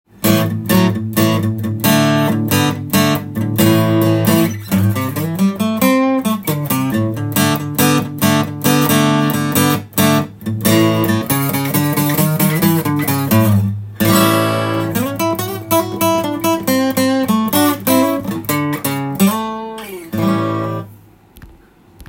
木が乾燥していて良い音がします。
一人でブルースを弾いても様になるギターです。